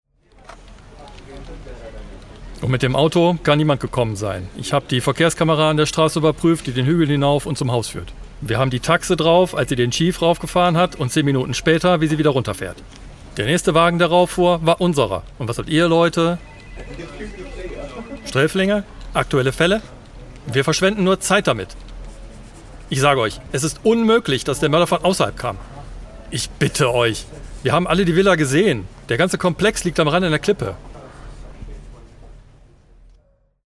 Hörspiel / Synchron
Stil: Argumentierend, eifrig, überzeugend, kollegialer Ton. Dynamische Beweisführung.